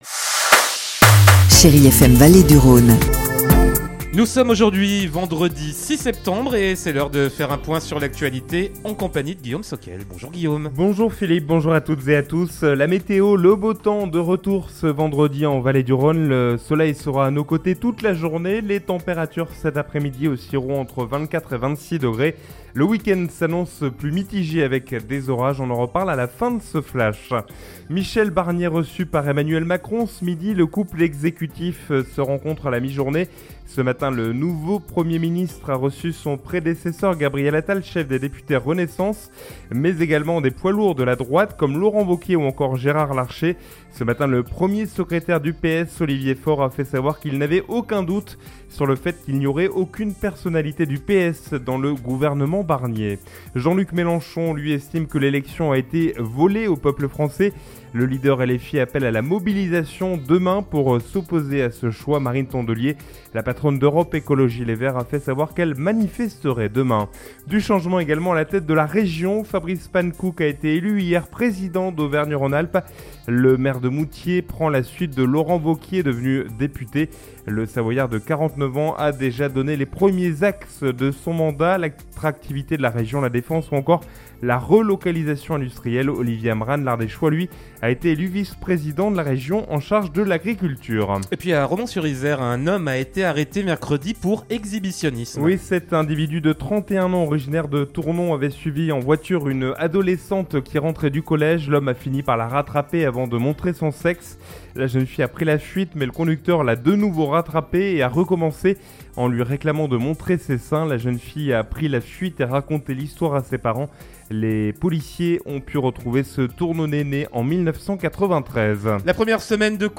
in Journal du Jour - Flash
Vendredi 6 septembre : Le journal de 12h